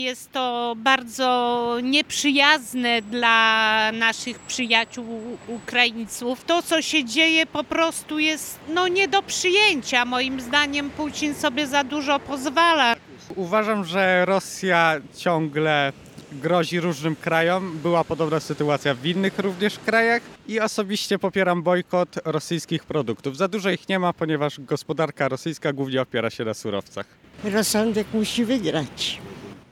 Sytuacja w Ukrainie. Komentują mieszkańcy Szczecina
SZCZ-Sonda-Ukraina.mp3